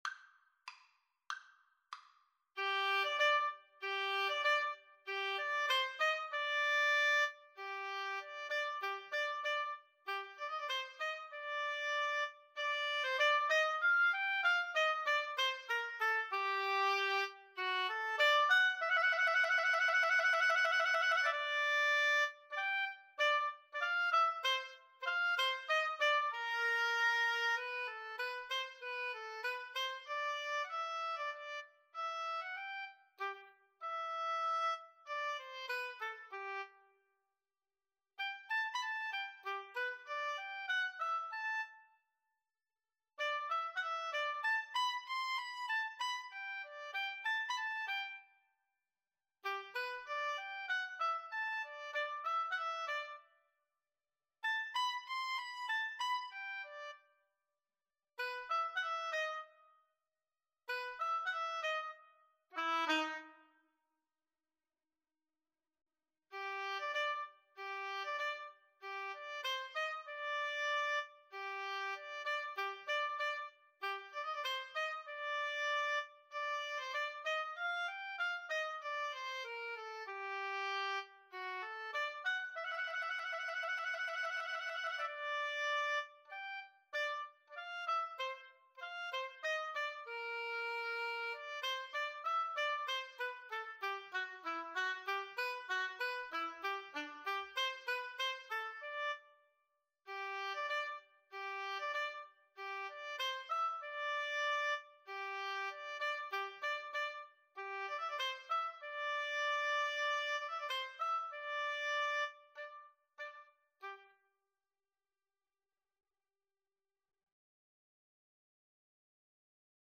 2/4 (View more 2/4 Music)
Allegro moderato =96 (View more music marked Allegro)
Oboe Duet  (View more Intermediate Oboe Duet Music)
Classical (View more Classical Oboe Duet Music)